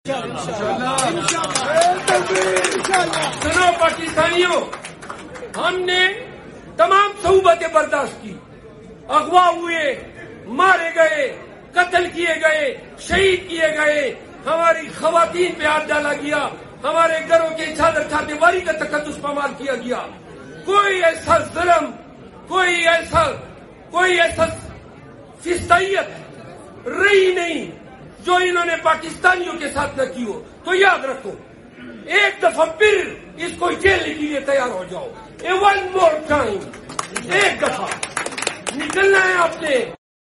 Sherafzalmarwat emotional speech sound effects free download